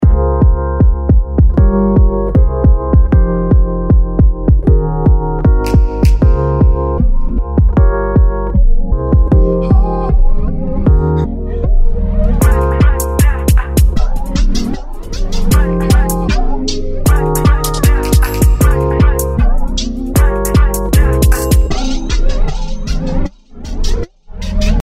Pulse Fiction」等もユニークで、エフェクトの積む順番も興味深く、トラック全体にかけて遊んでみました。